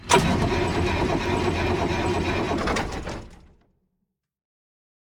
tank-no-fuel-2.ogg